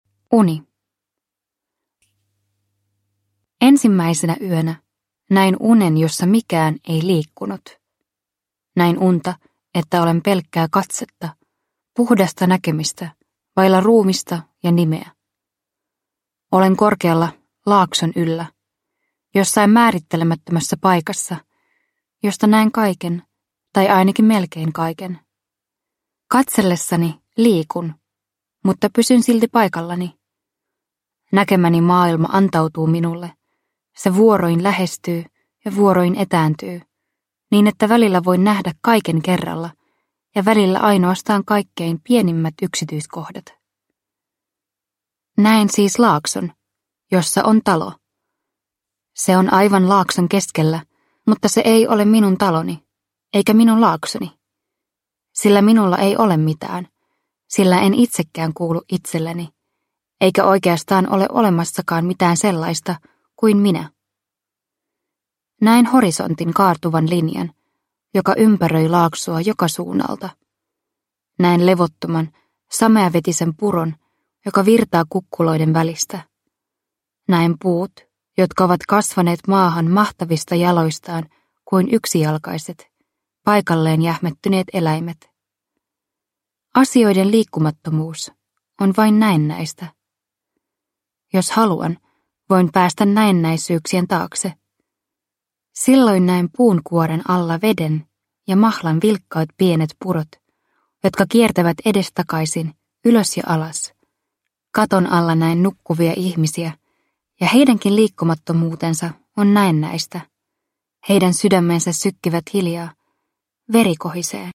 Päivän talo, yön talo – Ljudbok – Laddas ner